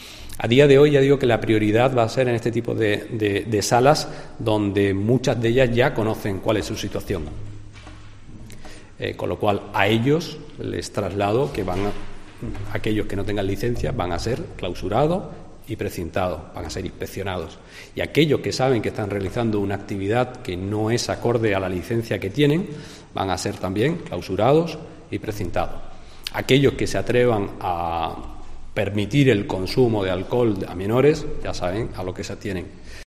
Felipe Arias, portavoz equipo de Gobierno en Huelva